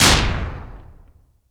HOLLY GUN 3.WAV